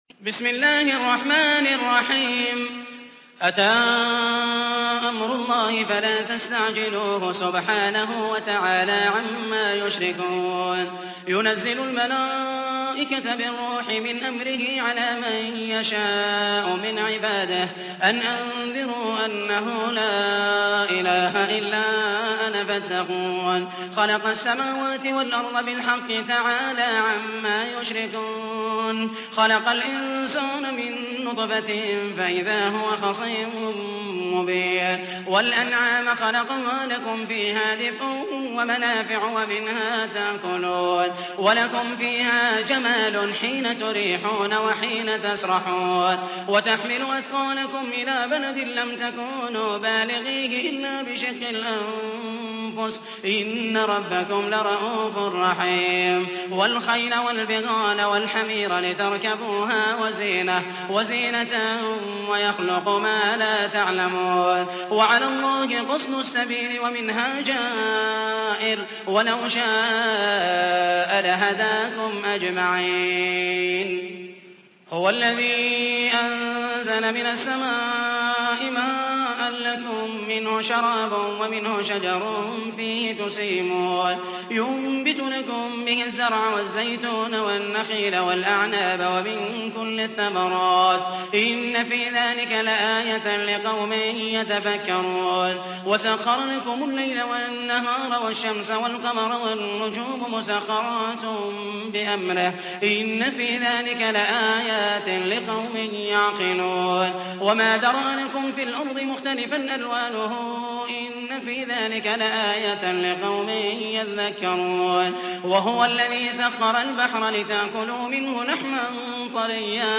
تحميل : 16. سورة النحل / القارئ محمد المحيسني / القرآن الكريم / موقع يا حسين